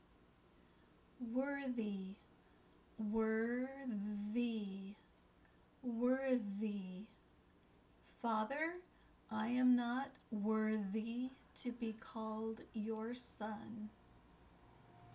Read the word, study the definition, and listen to how the word is pronounced. Then, listen to how it is used in the sample sentences.